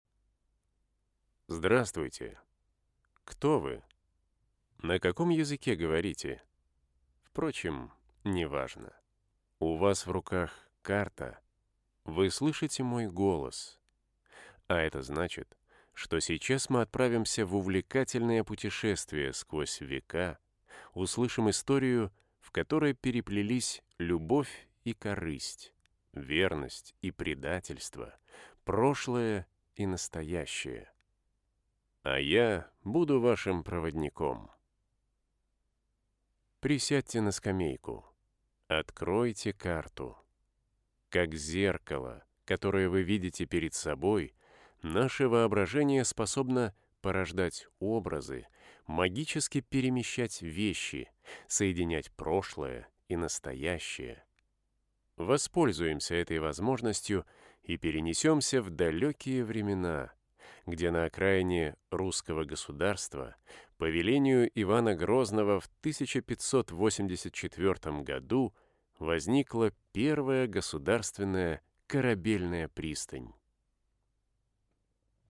Madrelingua